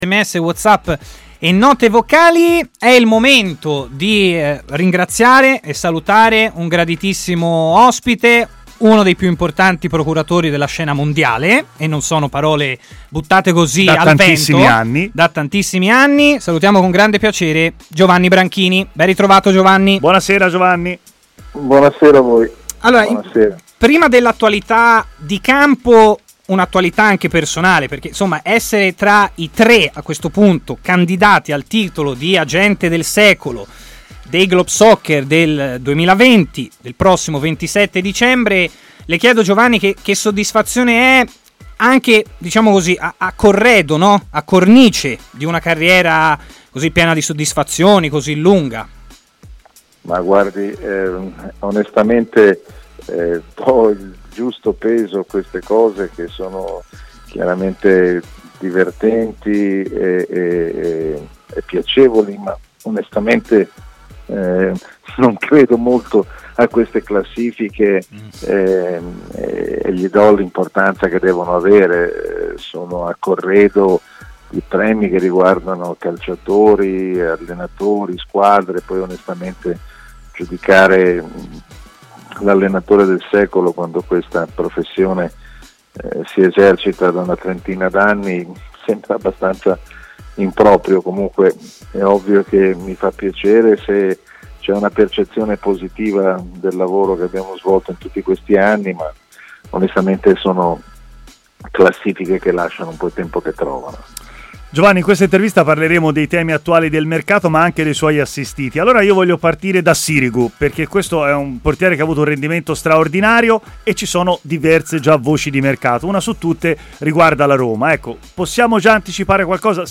intervenuto in diretta nel corso di Stadio Aperto, trasmissione di TMW Radio